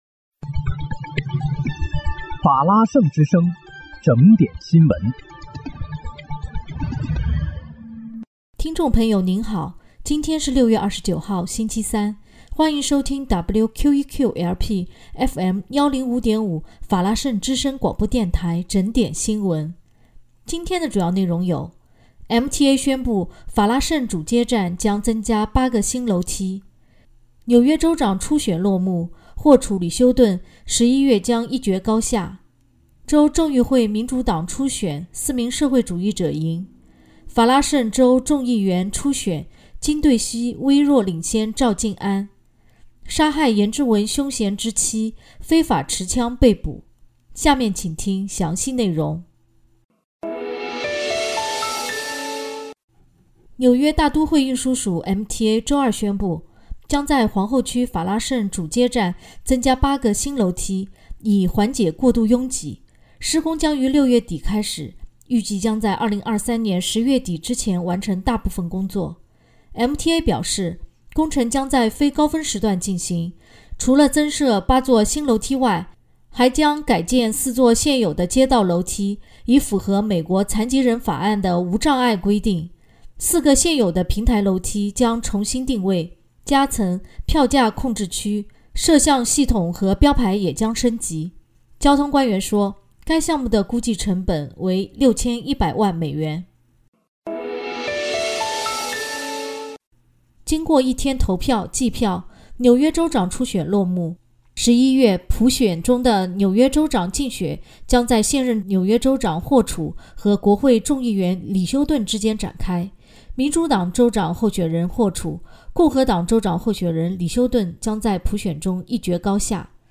6月29日（星期三）纽约整点新闻